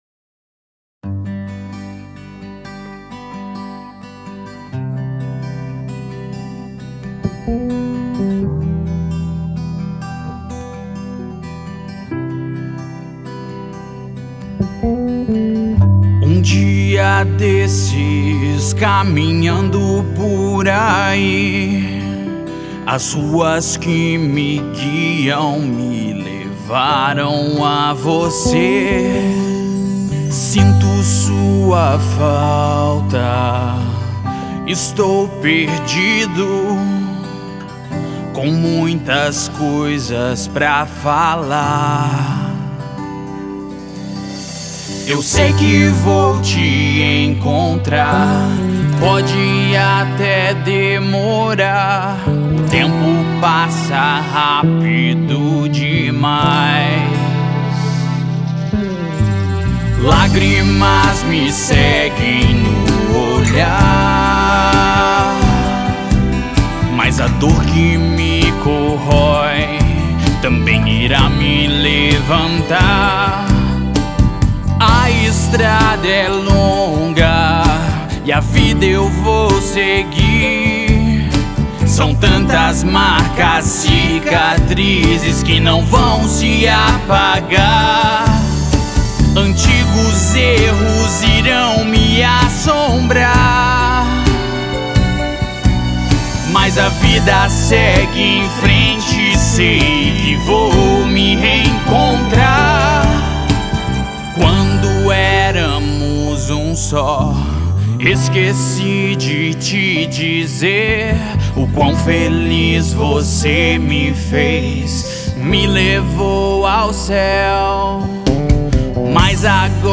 EstiloPop Rock